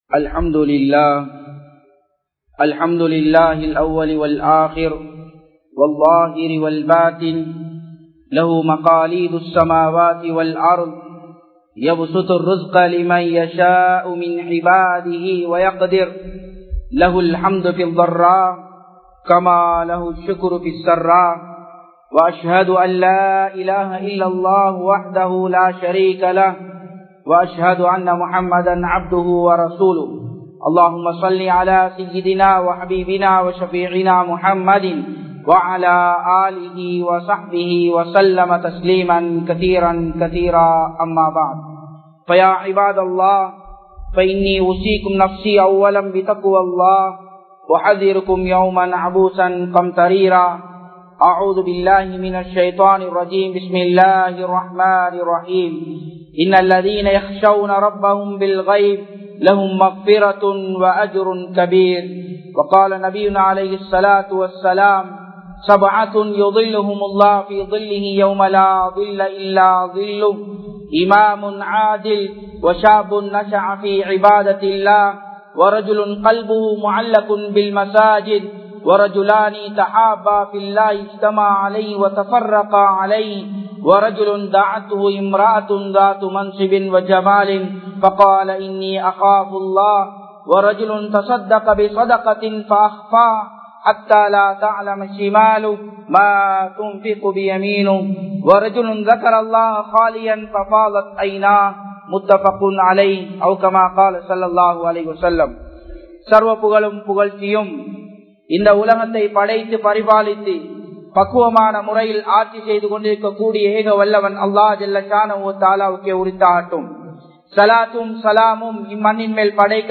Tholaipeasium Indraya Samoohamum (தொலைபேசியும் இன்றைய சமூகமும்) | Audio Bayans | All Ceylon Muslim Youth Community | Addalaichenai